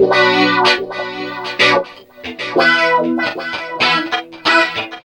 69 GTR 1  -L.wav